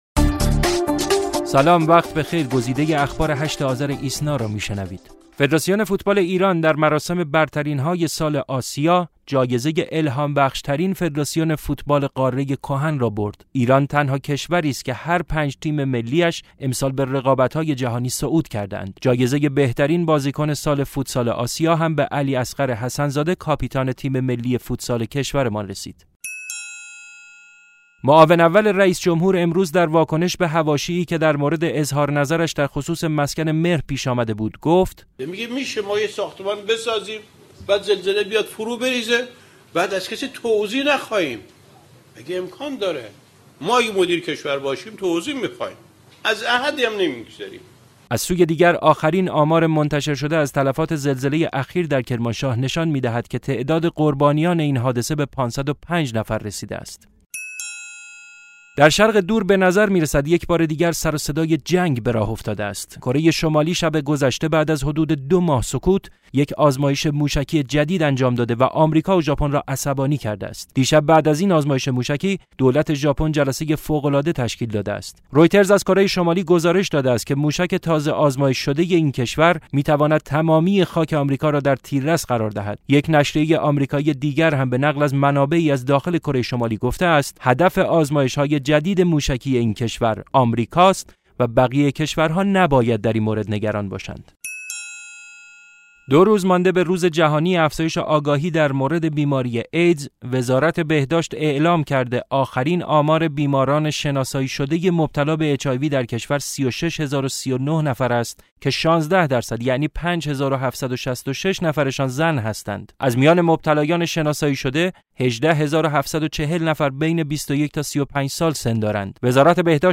صوت / بسته خبری ۸ آذر ۹۶